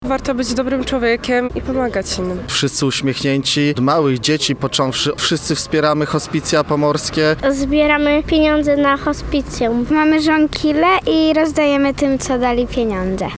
Posłuchaj co o akcji mówią uczestnicy: